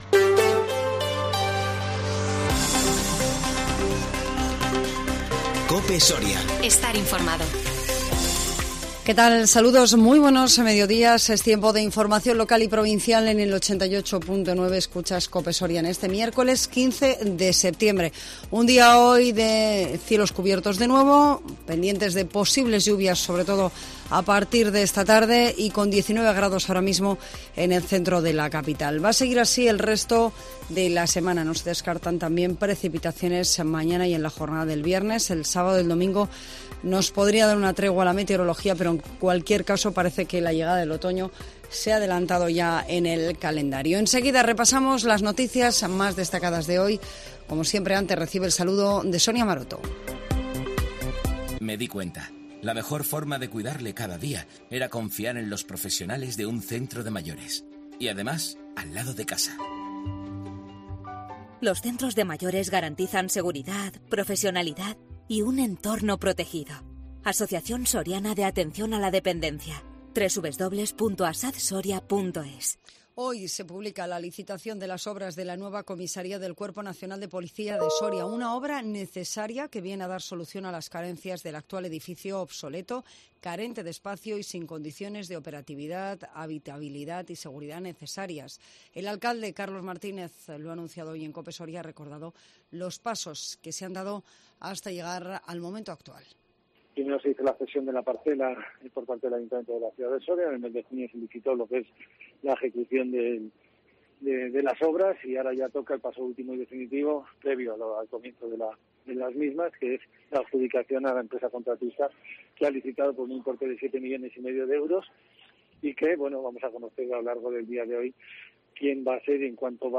INFORMATIVO MEDIODÍA 15 SEPTIEMBRE 2021